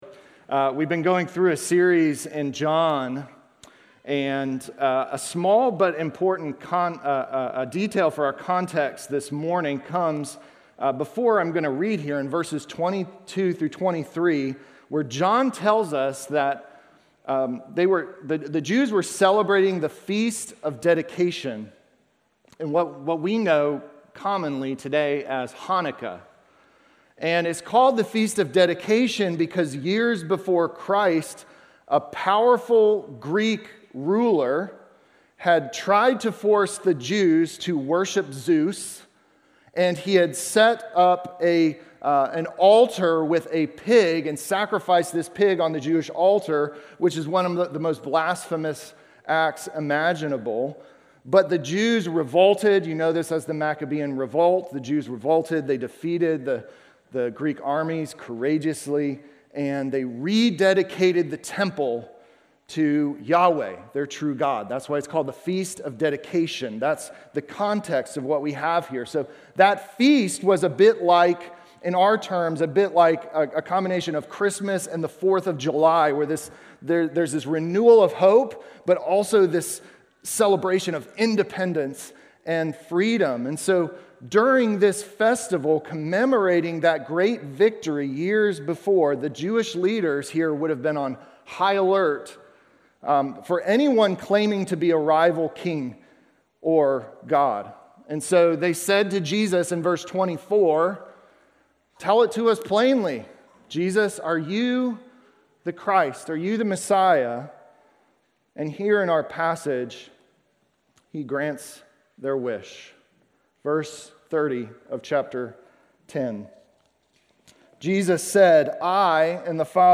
Sermon from November 2